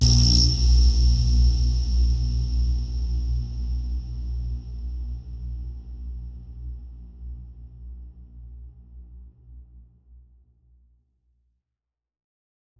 ShrinkingAnnouncement.wav